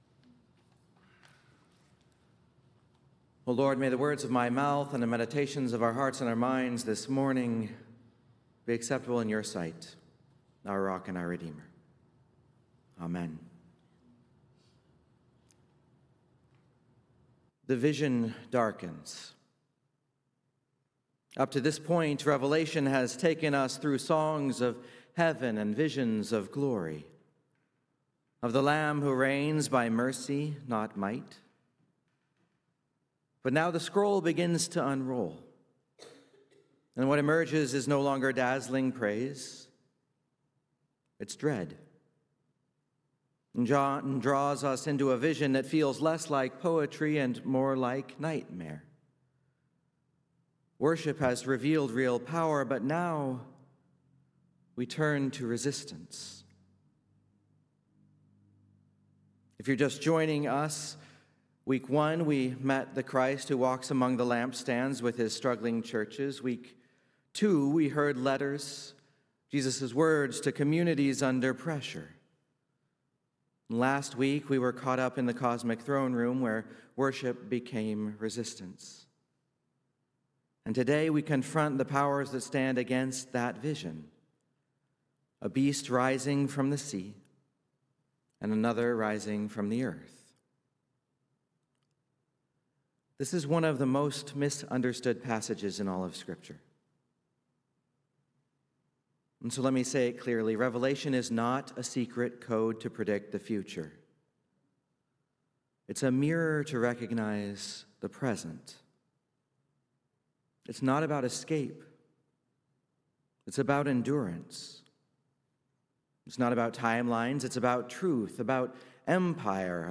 Sermons | Messiah Lutheran Church, Marquette